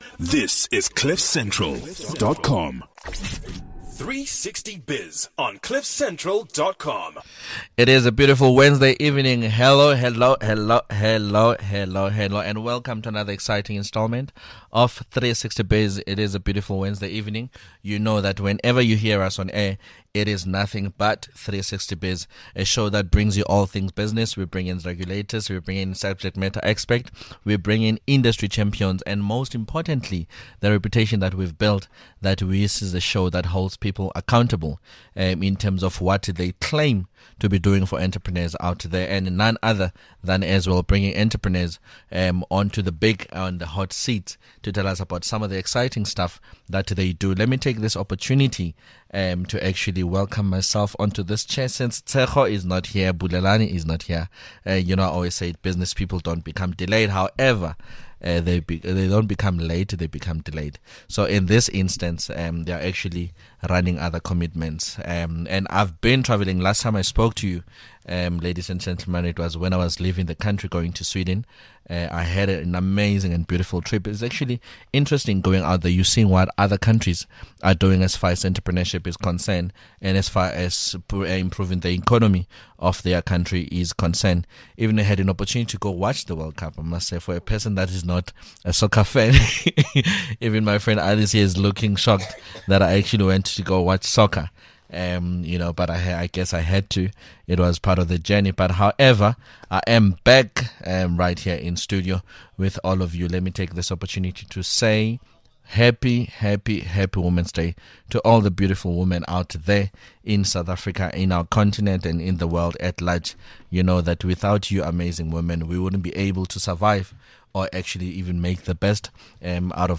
The team talks to women who are working in men’s worlds. They share some of their challenges and how they survive against all odds.